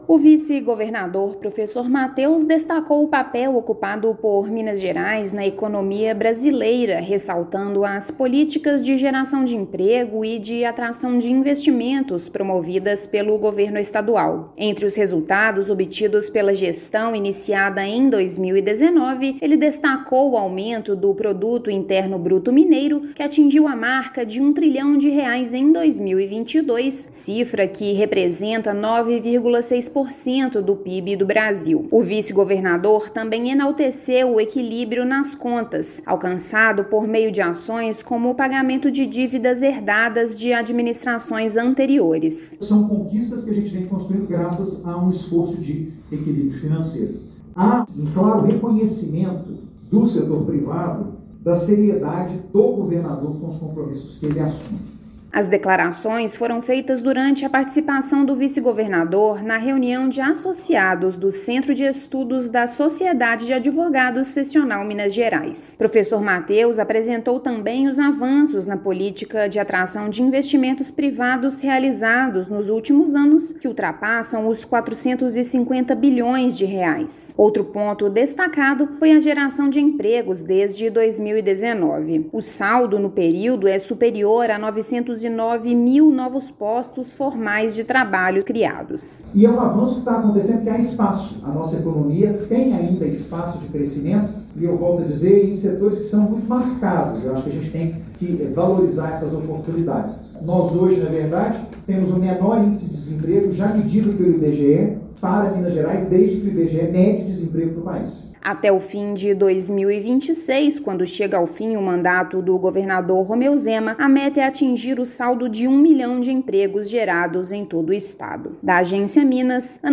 [RÁDIO] Vice-governador destaca o protagonismo de Minas no cenário econômico do país
Balanço com os resultados alcançados pelo Governo de Minas foi apresentado durante reunião dos associados do Centro de Estudos das Sociedades de Advogados. Ouça matéria de rádio.